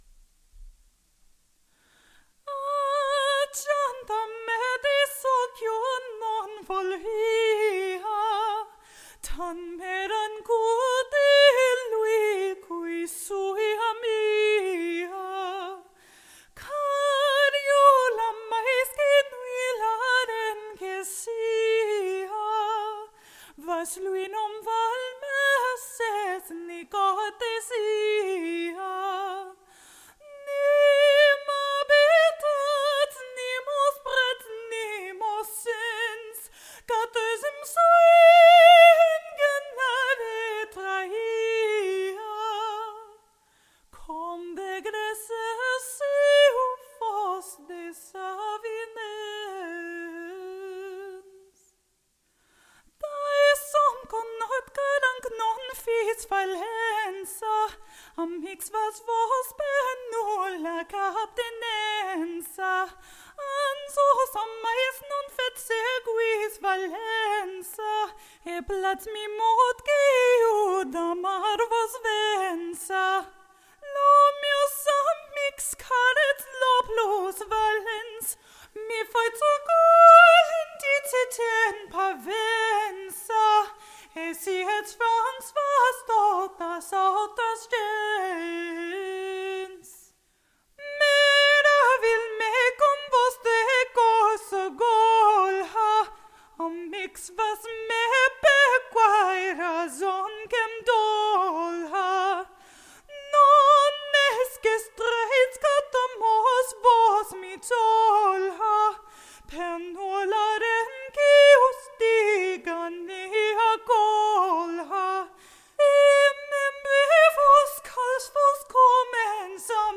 female vocalist, free rhythm
same melody each time, single vocalist